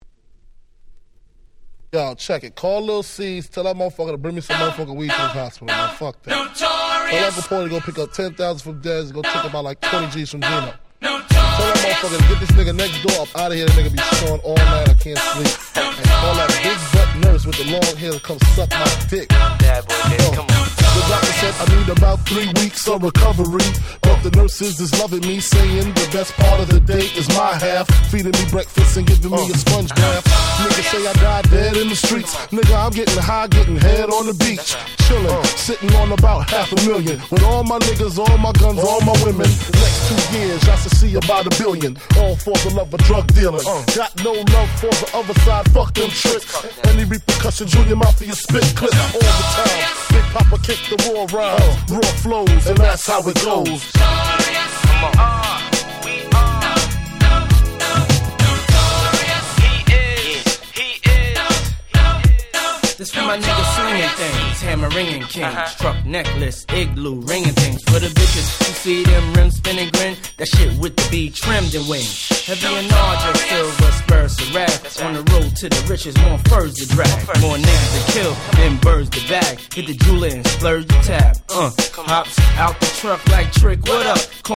99' Super Hit Hip Hop !!